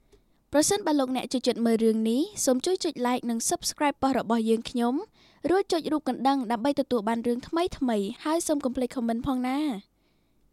Sonbida Media devoted to voice over service with 5 Years experience in voice over of worldwide languages. from narrative style and character voice acting including commercial, corporate video, games, Anime character voice acting